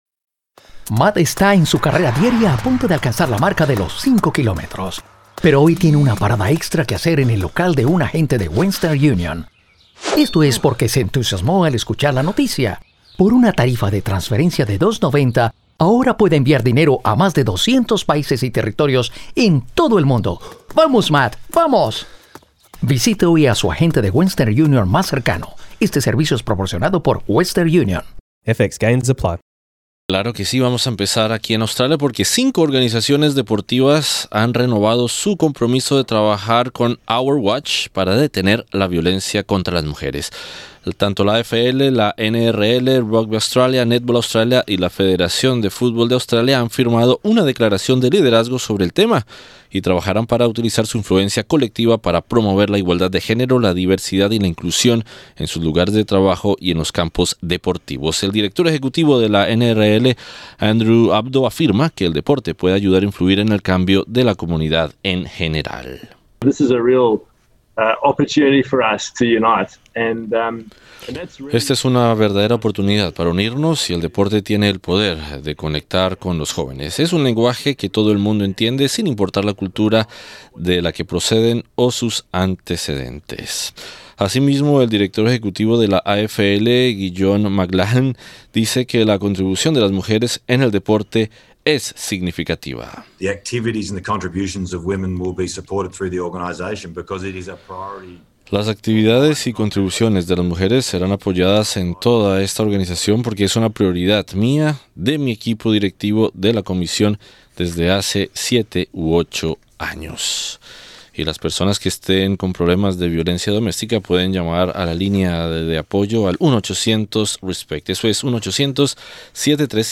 Cinco organizaciones deportivas nacionales australianas han renovado su compromiso de trabajar con Our Watch para detener la violencia contra las mujeres. Escucha esta y otras noticias deportivas del día.